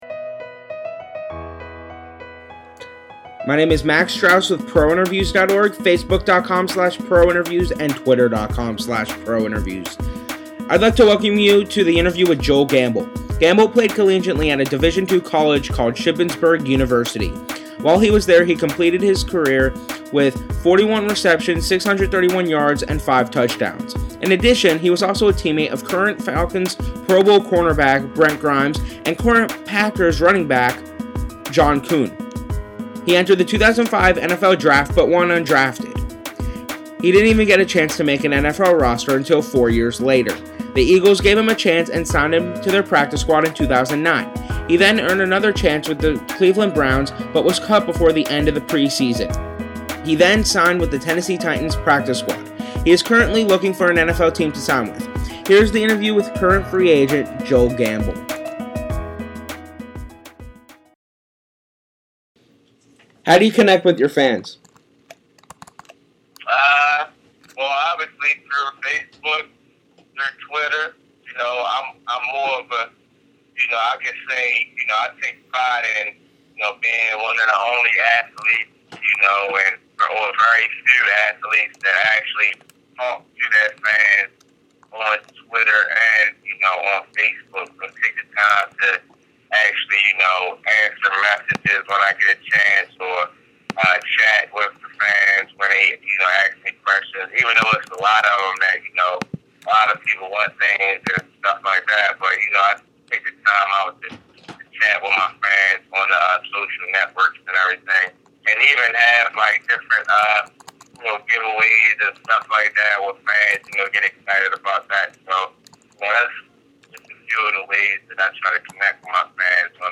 Afterwards, he signed with the Tennessee Titans practice squad. This interview was done towards the end of the 2011 NFL season.